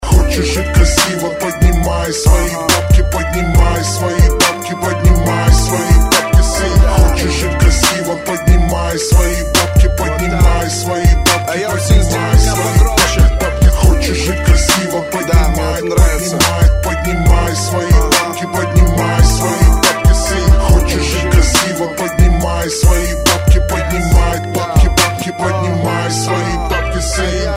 Rap, RnB, Hip-Hop